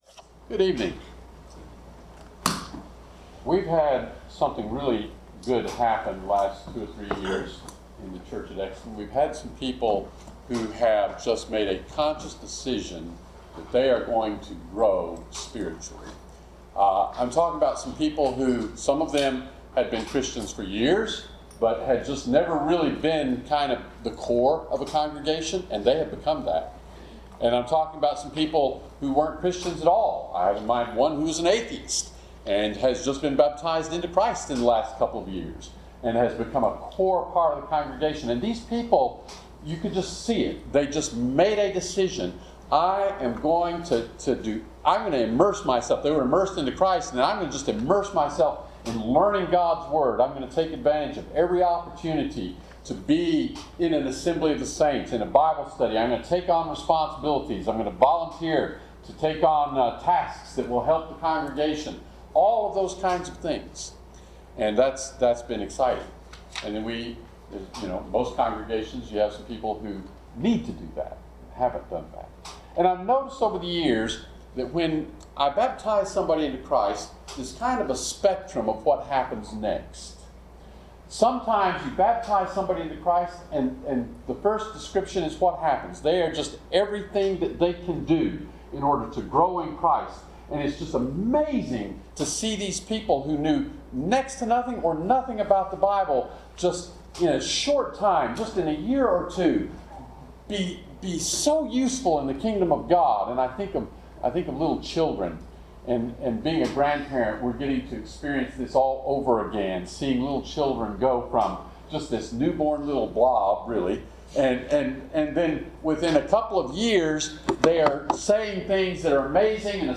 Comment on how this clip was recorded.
Series: GROW Conference Service Type: Sermon